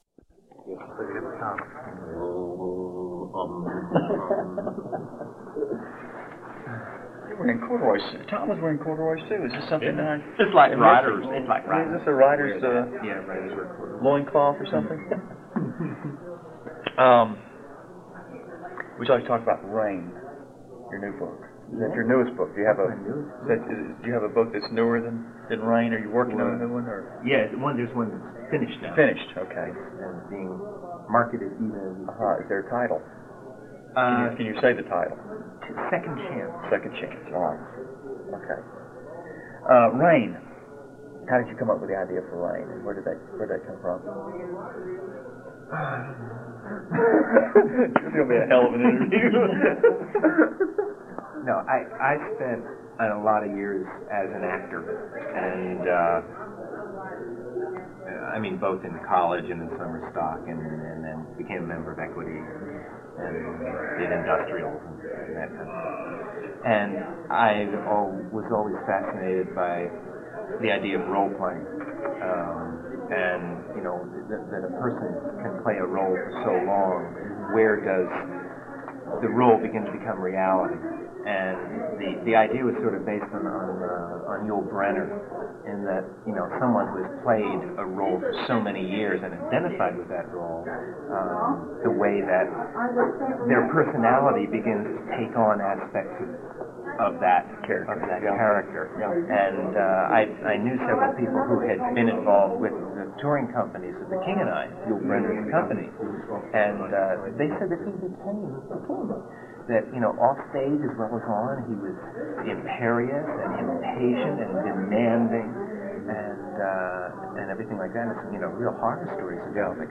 From the Archives: Robert McCammon interviews Chet Williamson at WFC 1990 – Robert McCammon
robert-mccammon-interviews-chet-williamson-wfc-1990.mp3